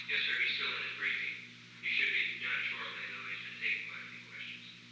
Secret White House Tapes
Conversation No. 917-31
Location: Oval Office
The President met with an unknown man.